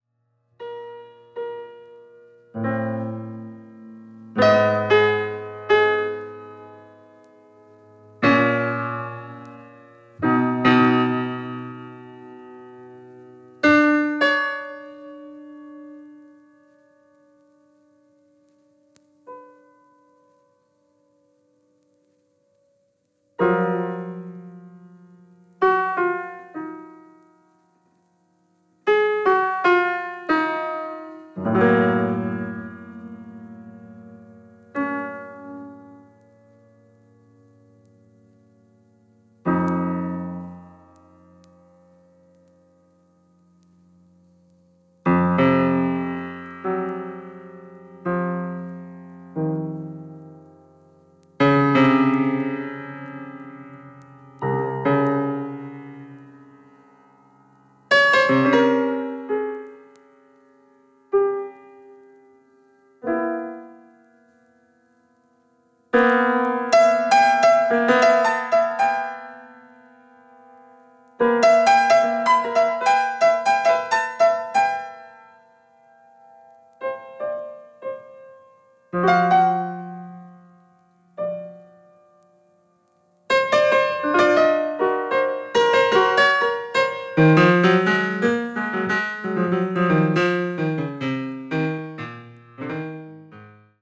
カナダ鍵盤奏者